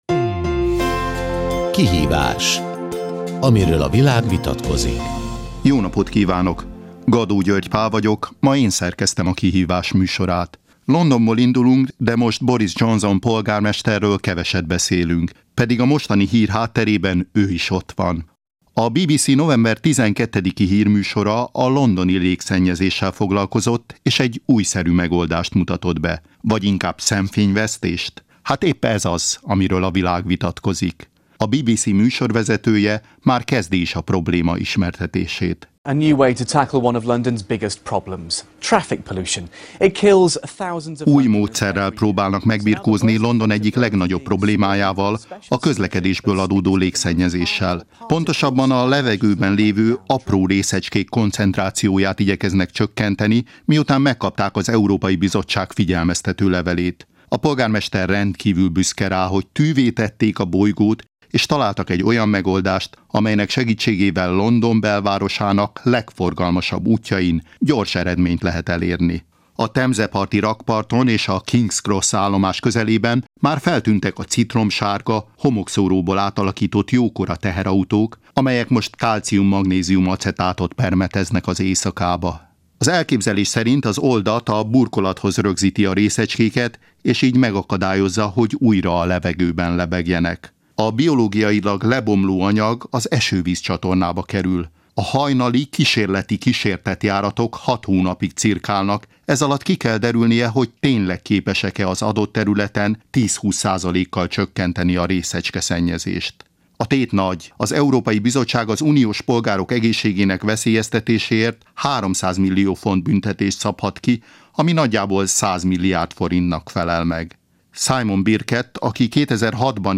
Interjúk a Korommentes levegőt az egészségért és az éghajlatért! konferenciáról
A Levegő Munkacsoport Korommentes levegőt az egészségért és az éghajlatért! című nemzetközi konferenciáján több interjú is készült a témában, mely a Kossuth Rádió Kihívás c. műsorában volt hallható, 2010. november 25-én.